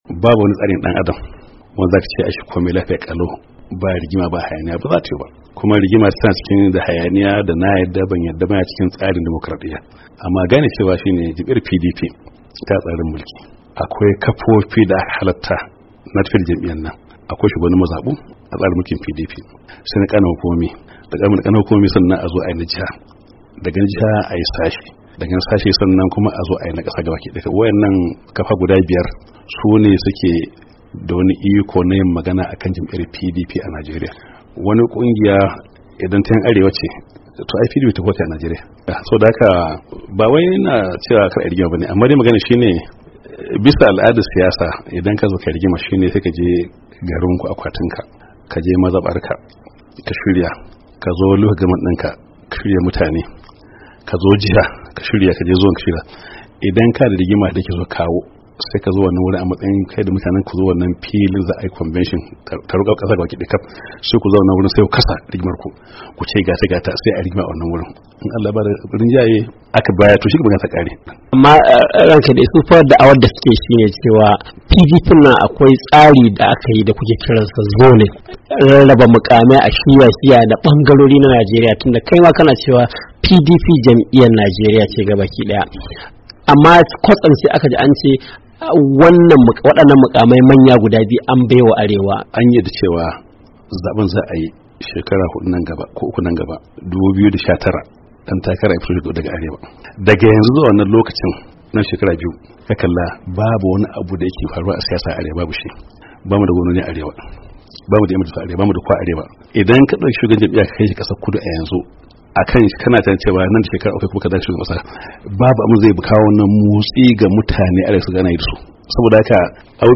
Saurari hira da Sule Lamido.